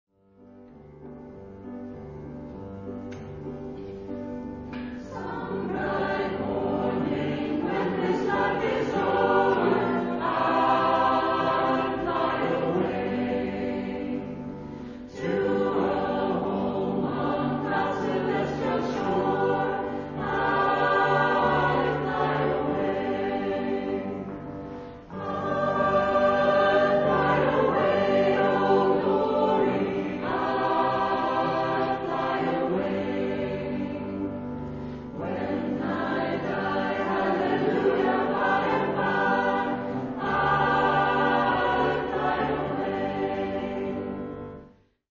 Gospel.
SATB a cappella.
Register: S:D4-D5, A:B3-A4, T:G3-E4, B:C3-G3
Besättning: SATB